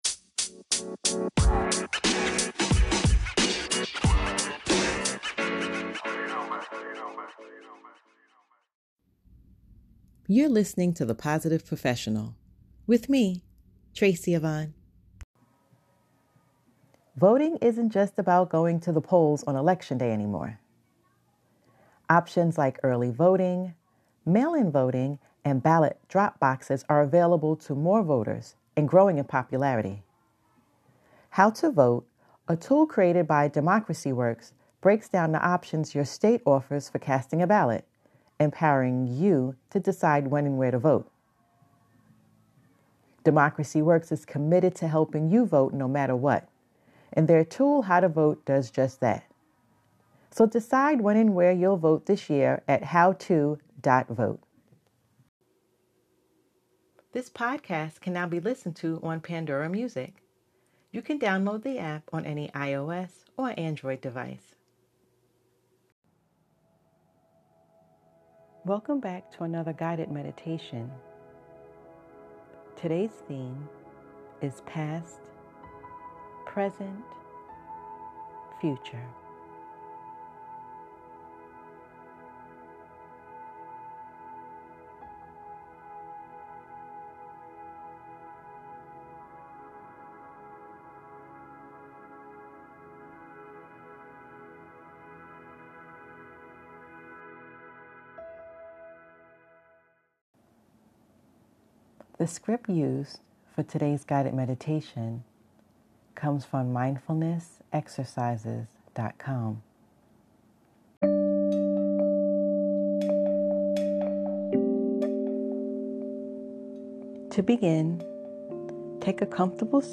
Guided Meditation - Past, Present, Future